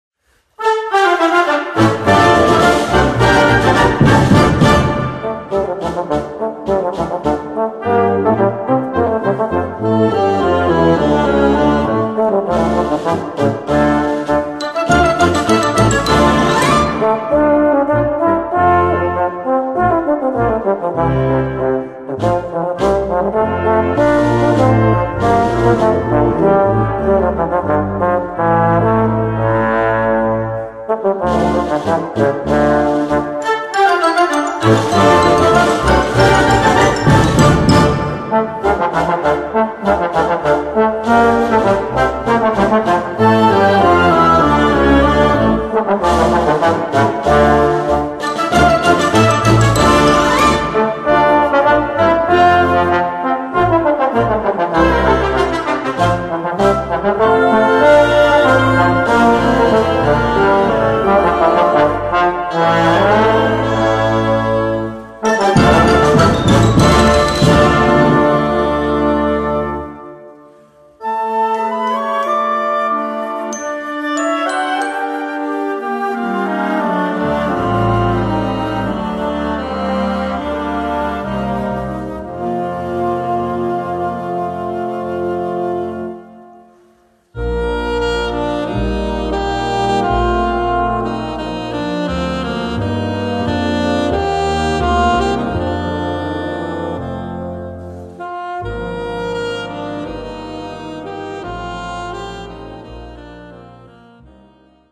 for Concert Band